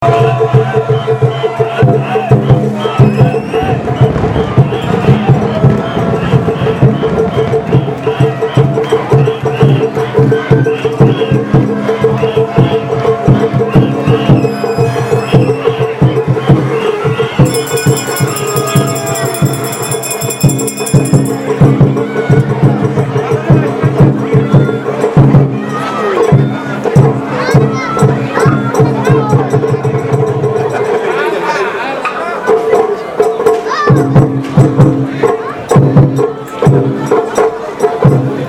・　秩父夜祭の熱気に溢れる音をアップしました。
◎　屋台を引く掛け声（１）
坂を上った広場には多くの屋台と引き手が大群衆になってひしめき合っていました。